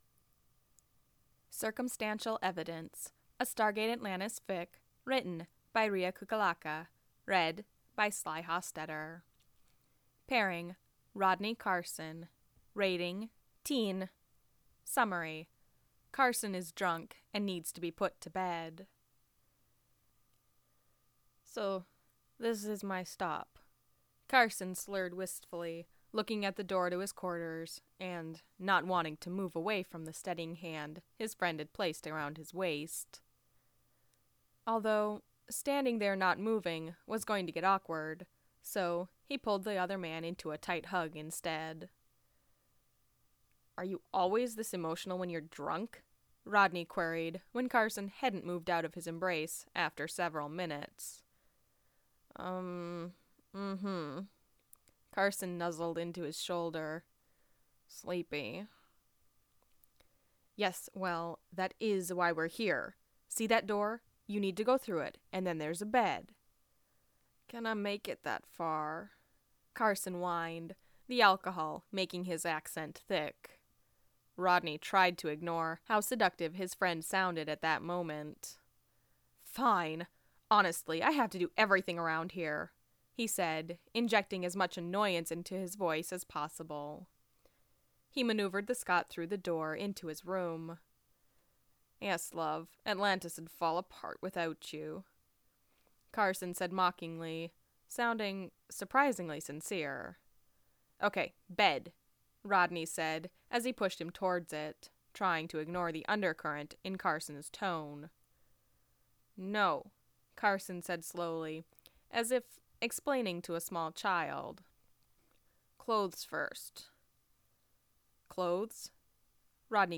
info: anthology|single reader